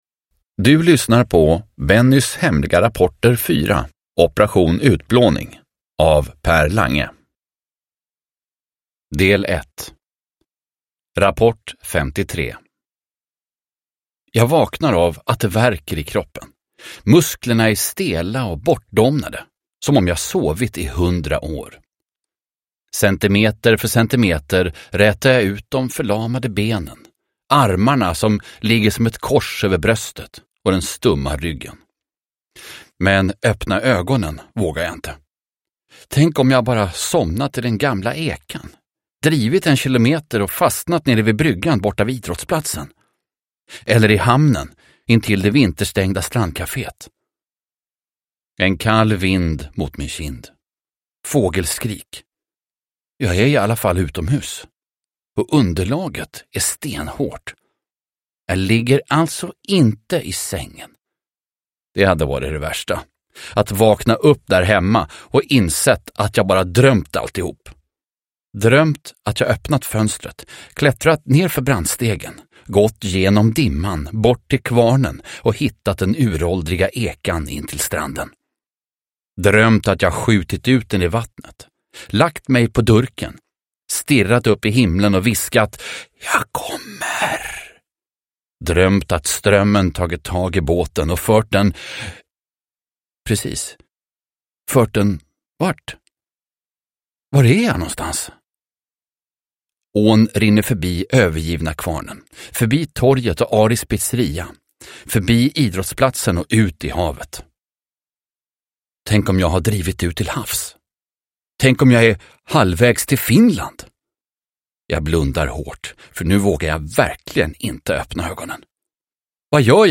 Operation: Utplåning – Ljudbok – Laddas ner